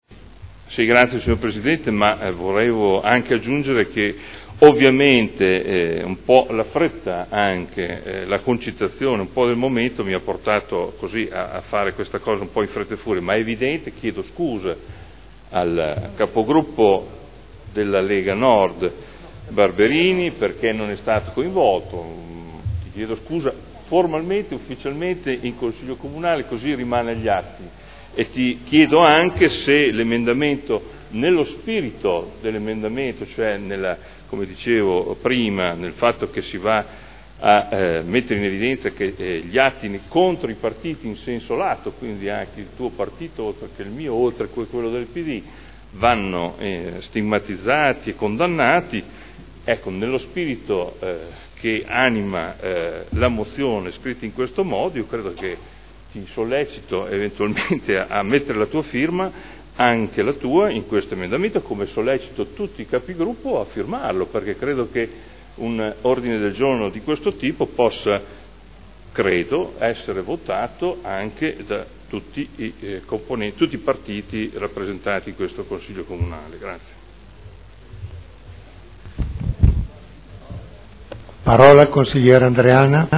Seduta del 30/05/2011.